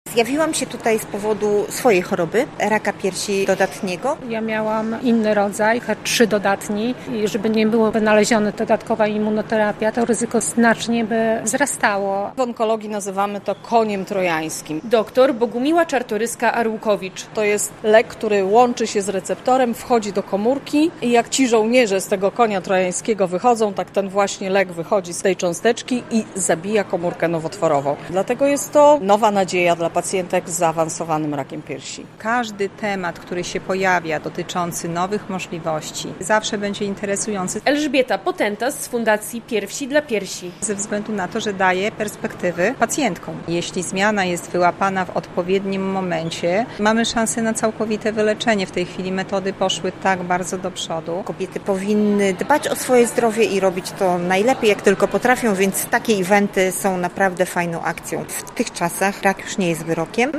Spotkanie edukacyjne HER2-low - relacja